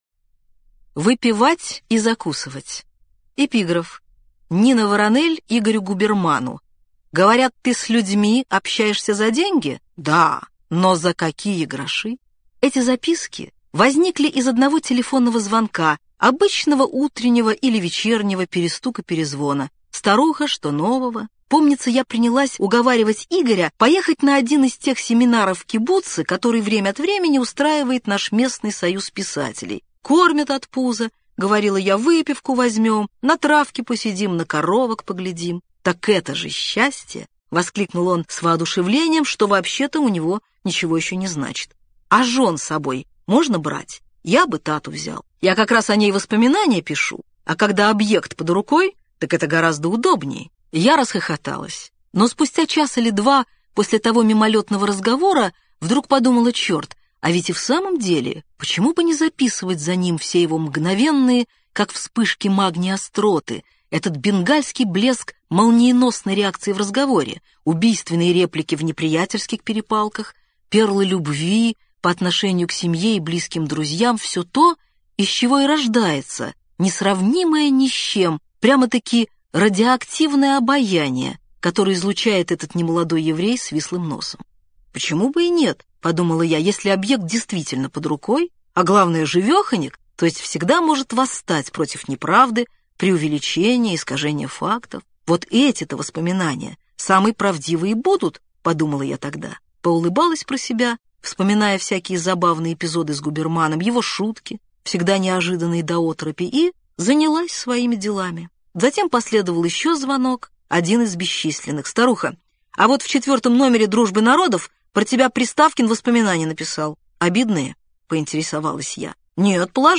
Аудиокнига Альт перелетный | Библиотека аудиокниг
Aудиокнига Альт перелетный Автор Дина Рубина Читает аудиокнигу Дина Рубина.